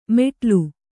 ♪ meṭlu